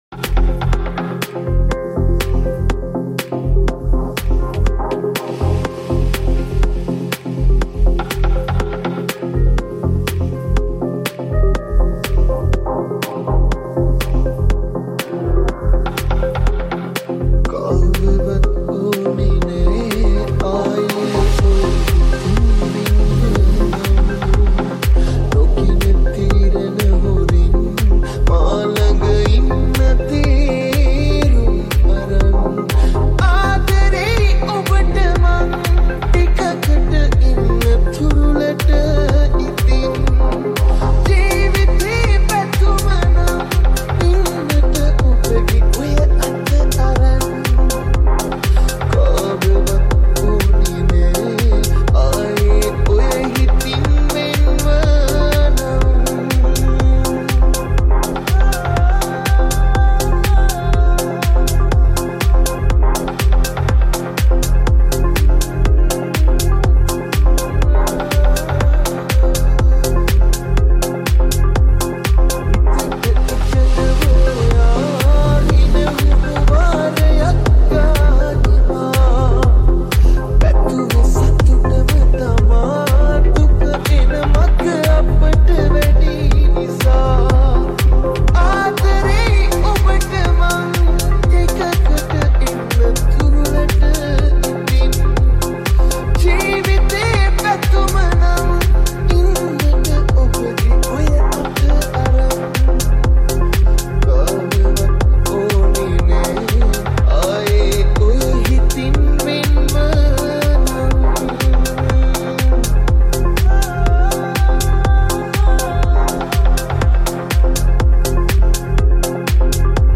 Progressive Mix